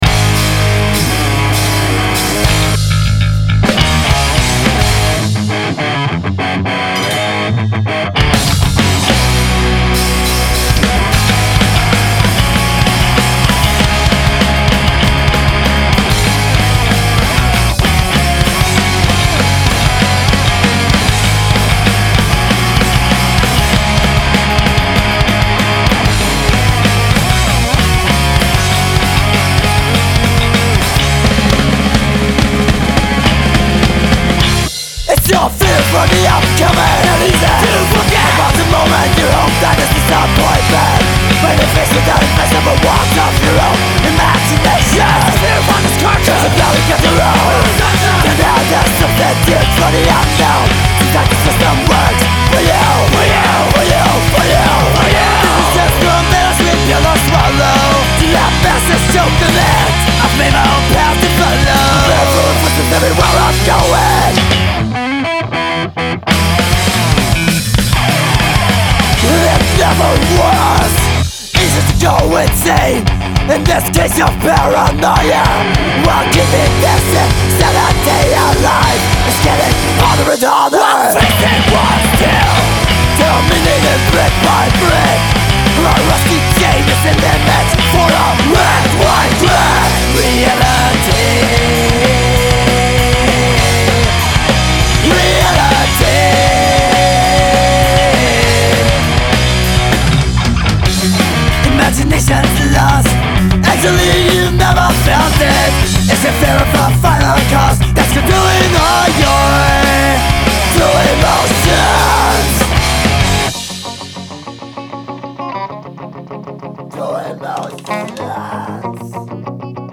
have been recorded at STRESS Studio